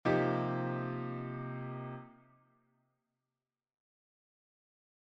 Improvisation Piano Jazz
Accord So What
Sur un accord mineur :